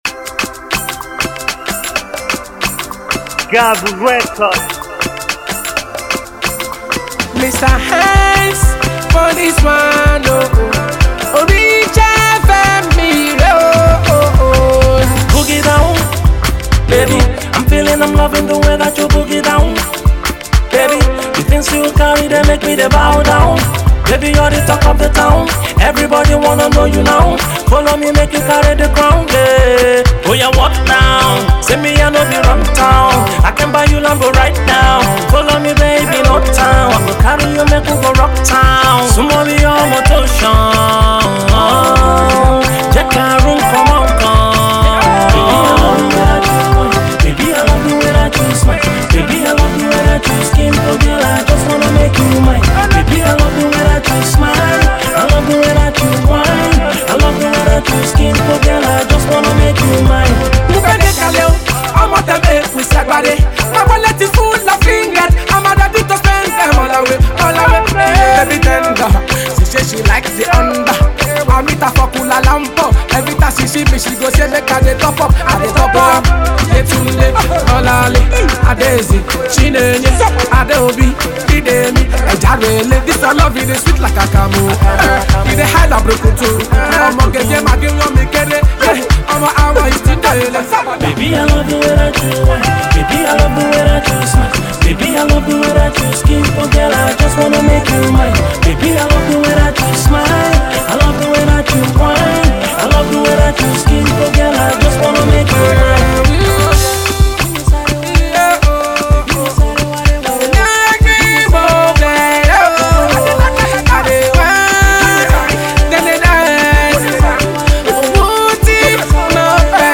street anthem